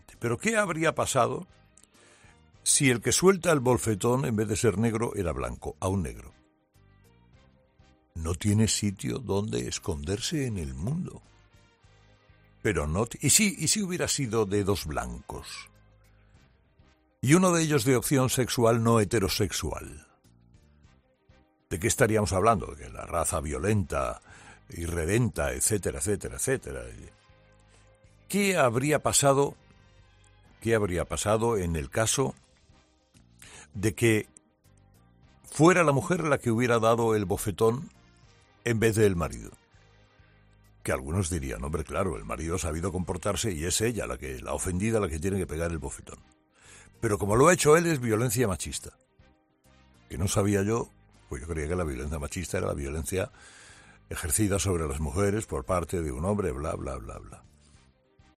En su monólogo de este lunes, Carlos Herrera ha querido explicar su opinión sobre lo sucedido, poniendo énfasis en la obsesión de Hollywood por lo políticamente correcto.